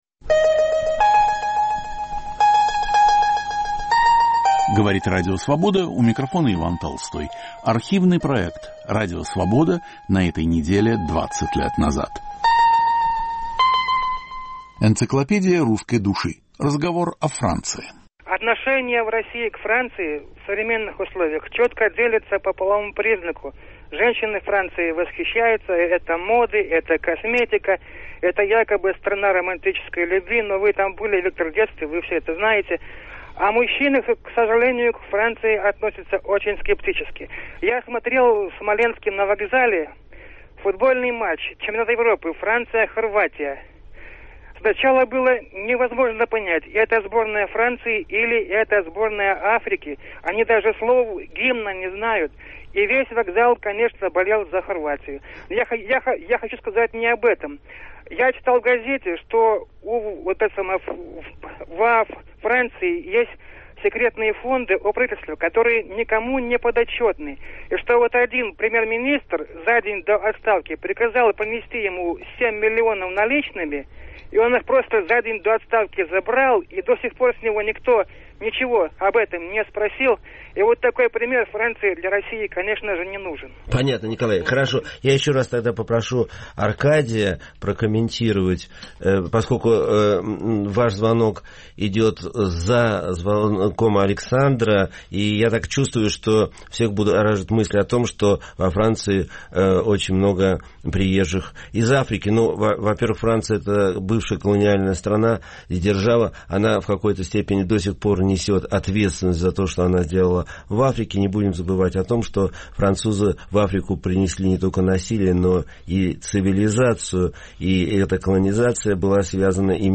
Автор и ведущий Виктор Ерофеев.